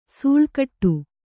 ♪ sūḷkaṭṭu